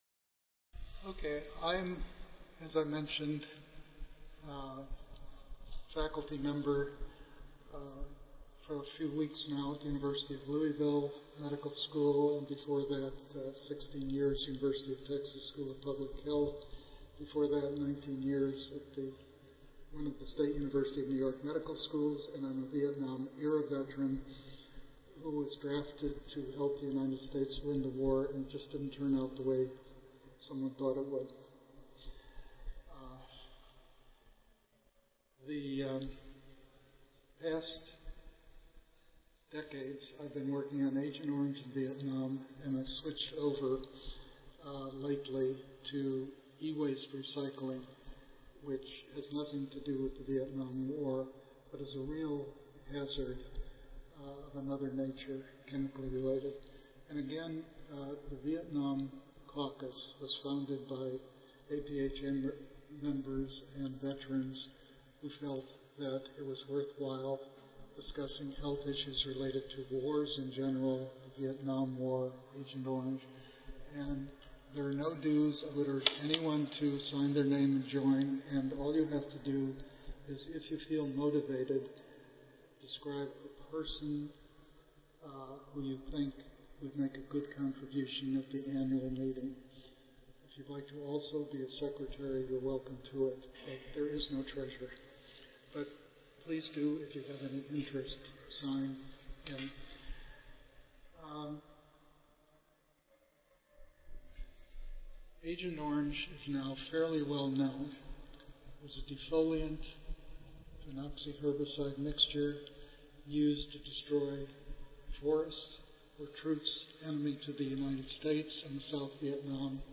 142nd APHA Annual Meeting and Exposition (November 15 - November 19, 2014): Agent Orange and Dioxin Chronology; Past, Present and Future
Recorded Presentation